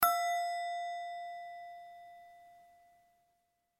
Звуки музыкального треугольника
Низкий тон голоса